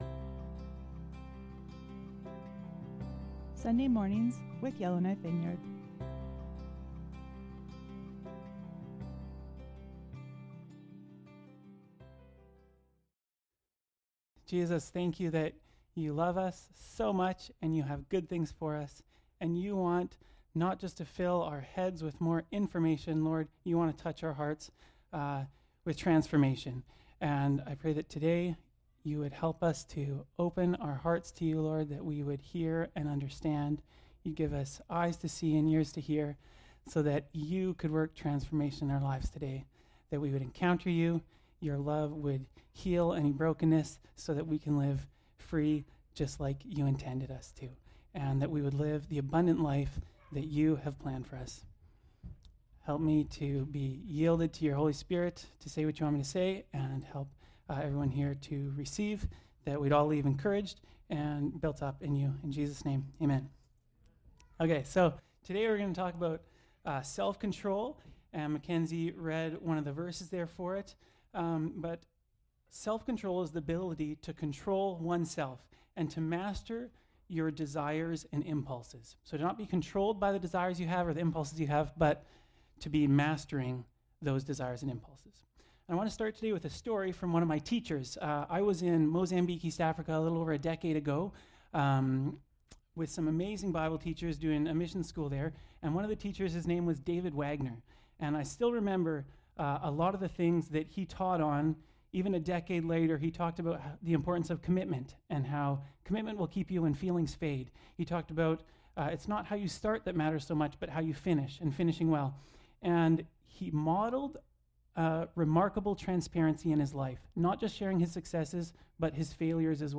Sermons | Yellowknife Vineyard Christian Fellowship
Guest Speaker